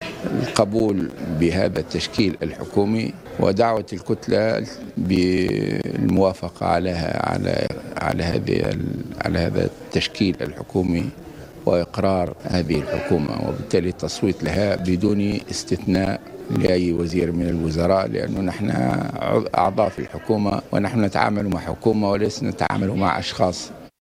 ودعا الغنوشي في تصريح صحفي، الكتلة البرلمانية للحركة إلى التصويت على التحوير الوزاري وعلى جميع الوزراء، مضيفا أنهم يتعاملون مع حكومة وليس مع أشخاص.